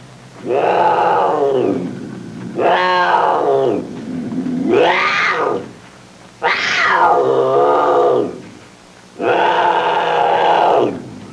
Black Leopard Sound Effect
Download a high-quality black leopard sound effect.
black-leopard.mp3